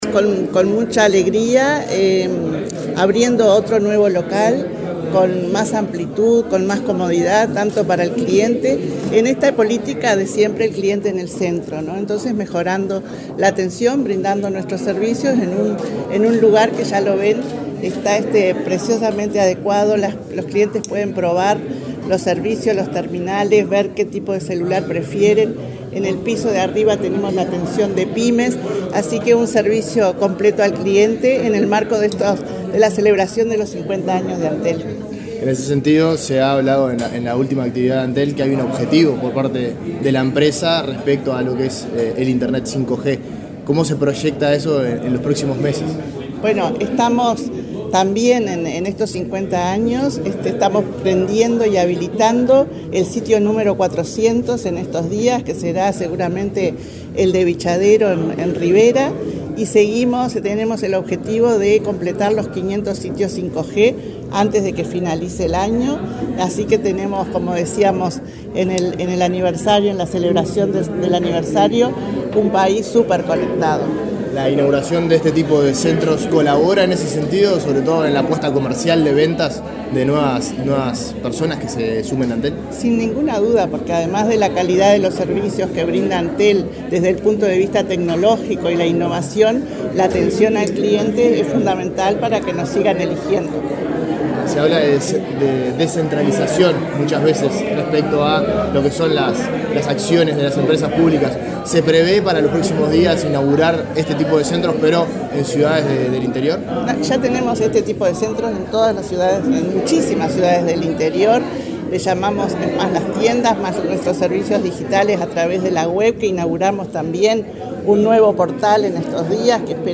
Declaraciones de la presidenta de Antel, Annabela Suburú
La presidenta de Antel, Annabela Suburú, dialogó con la prensa, al participar en la inauguración de un local de la empresa en el edificio El Gaucho,